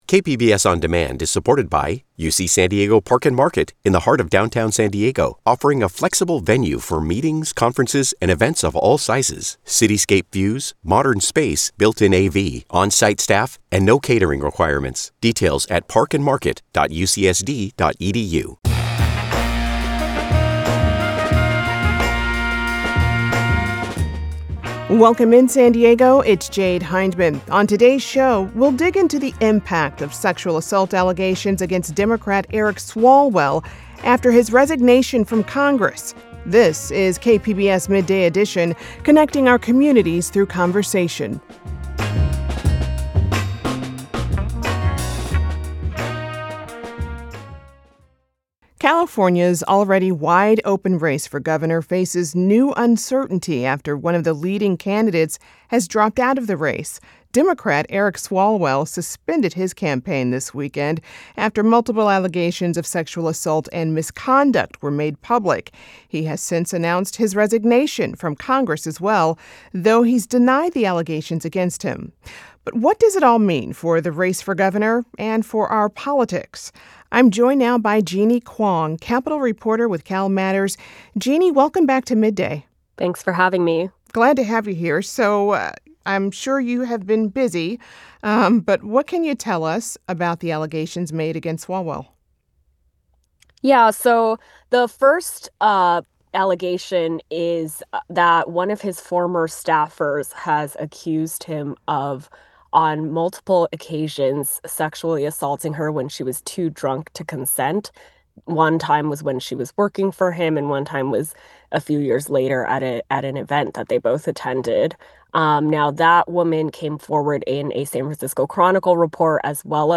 On Midday Edition, we discuss the allegations and how the race for California's next Governor has changed, as well as what the allegations reveal about politics today. Editor's note: This interview was recorded before Rep. Eric Swalwell of California submitted more information on his resignation from Congress.